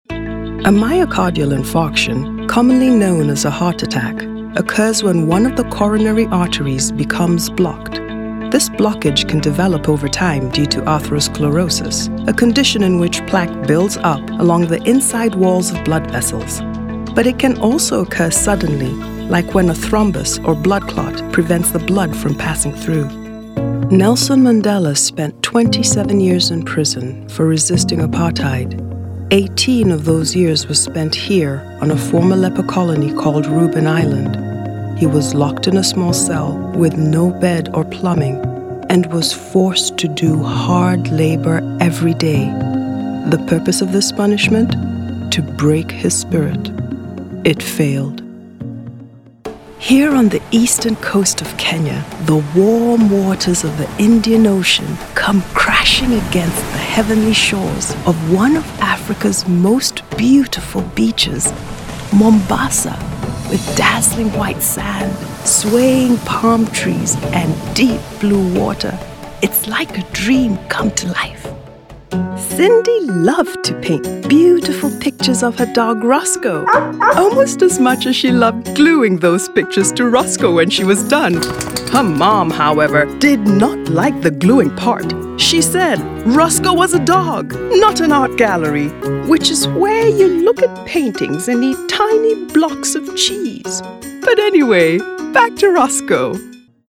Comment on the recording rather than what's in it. Equipment Mac, Adobe Audition, Sennheiser mkh 416, Audient id14 interface